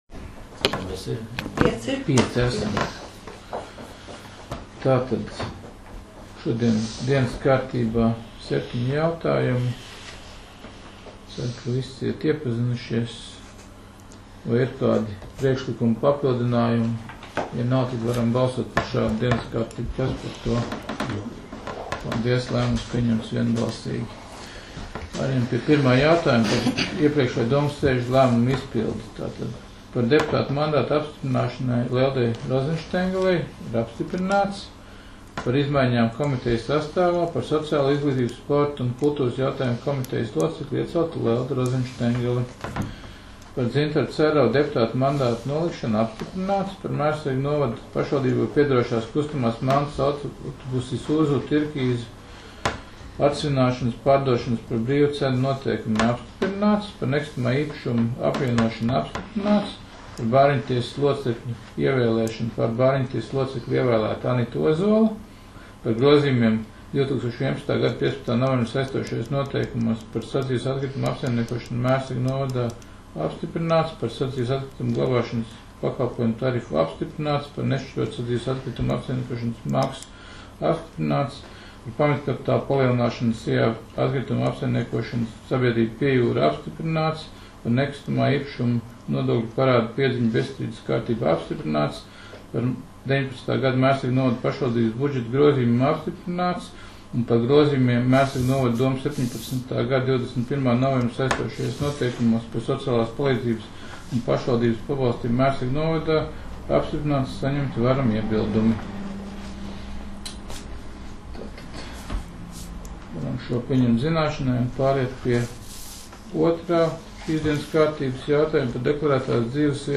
Mērsraga novada domes sēde 18.06.2019.